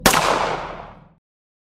pistol_fire_sfx.mp3